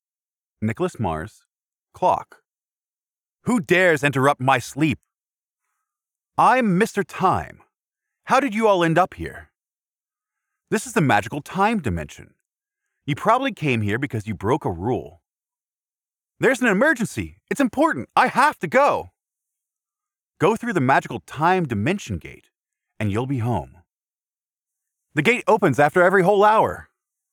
角色配音-灵活多变